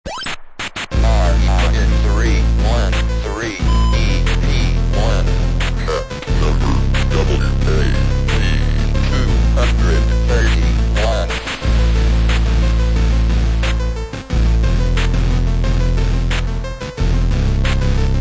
Hitech funk
Electro